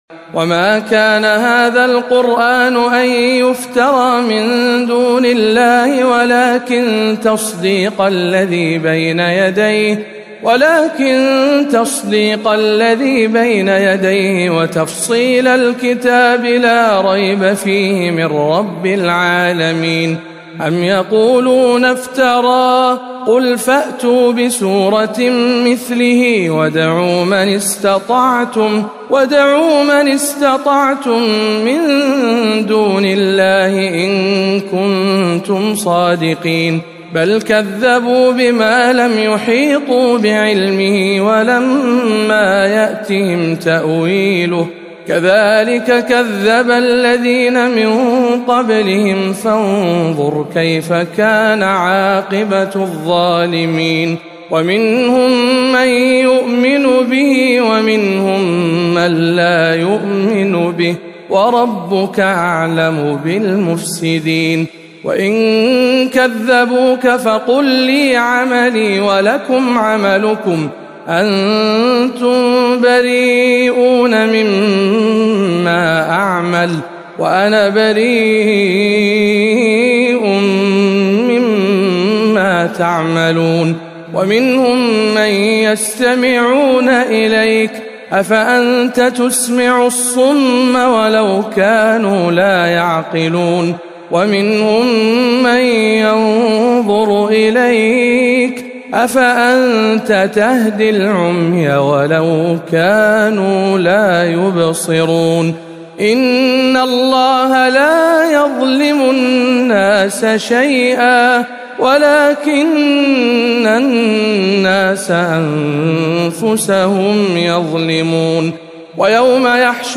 تلاوة مميزة من سورة يونس